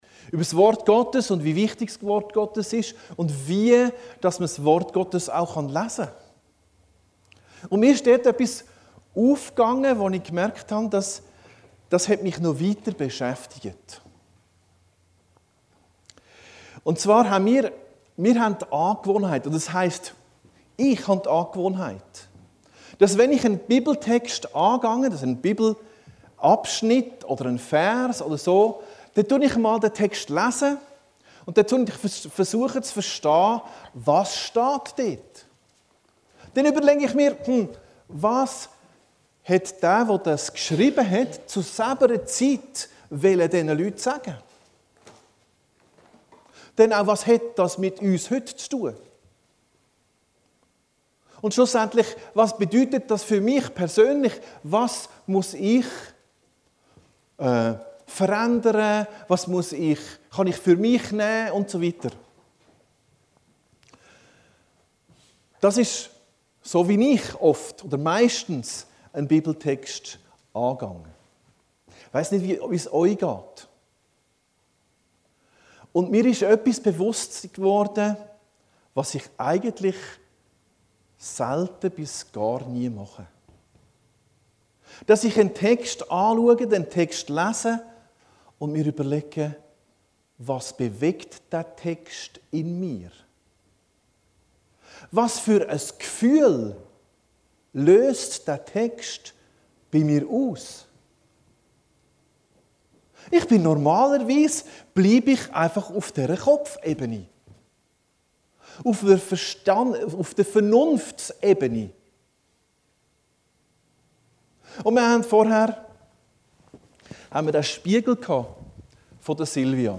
Predigten Heilsarmee Aargau Süd – Das Wort Gottes Psalm 23